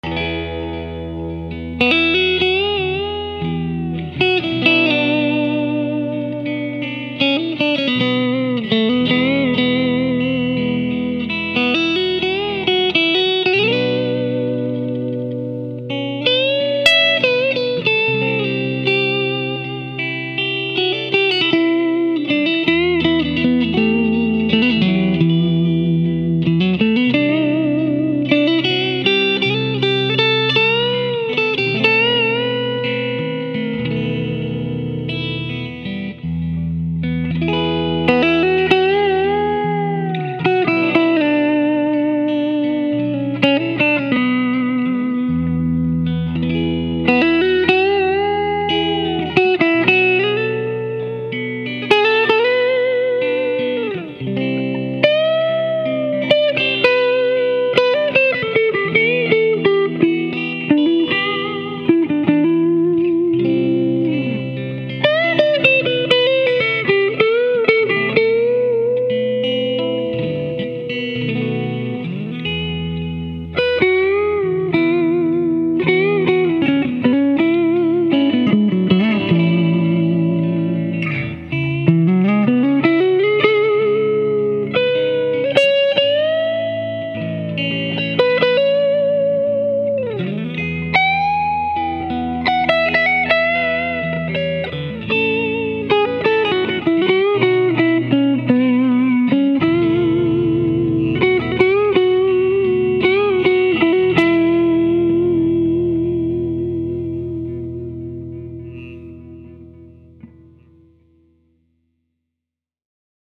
I quickly recorded a sound clip of how Goldie sounds clean. In the “rhythm” part, I have both humbuckers going. It has a sweet, chimey, and big tone. The first part of the solo features the bridge pickup in single-coil mode. The second part to the end features the bridge pick in full humbucker configuration.
goldieclean.mp3